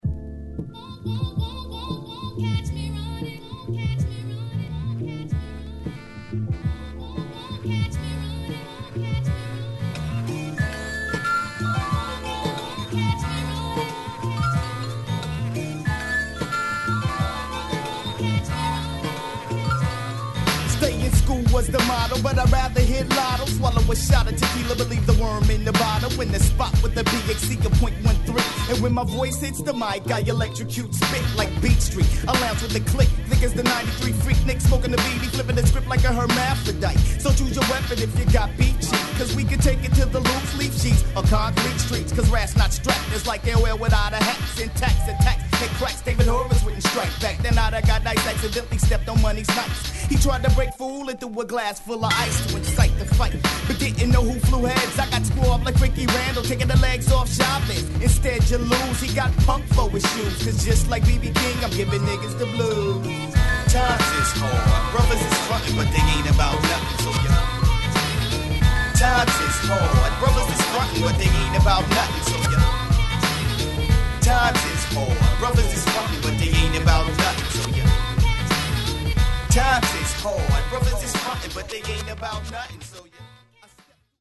ホーム HIP HOP UNDERGROUND 12' & LP R